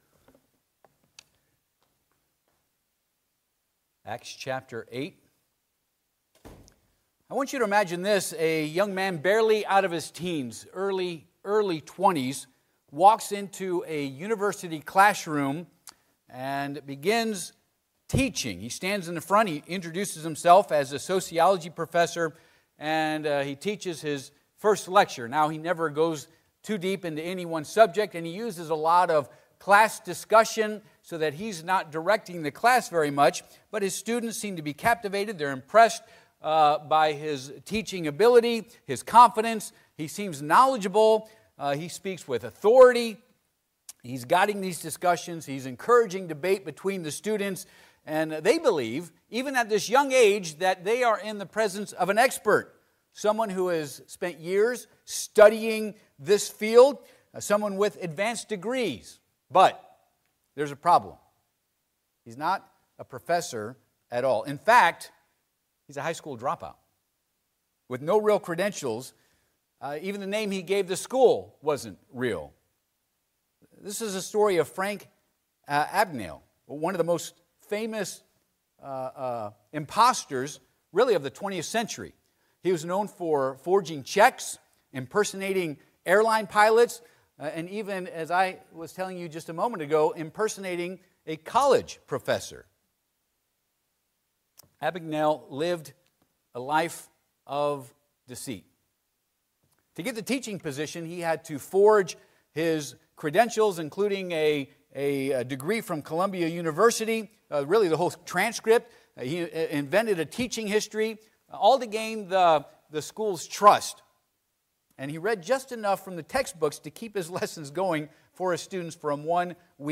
Acts 8:9- Service Type: Sunday AM « Parting out the Promised Land